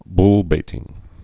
(blbātĭng)